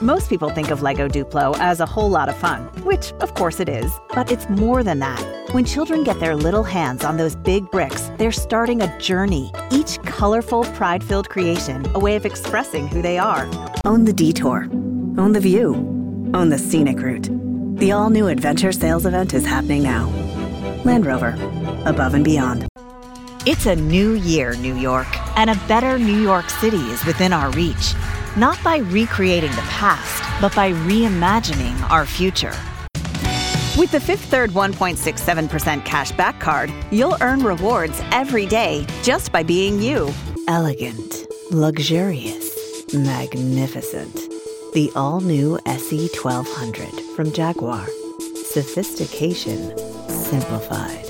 Bring your message to life with a professional voiceover that connects, converts, and is unmistakably human.
Commercials